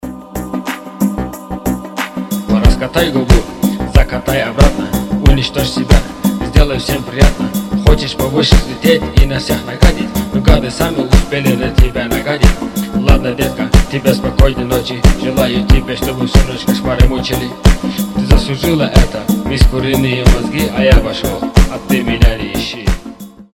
• Качество: 320, Stereo
ритмичные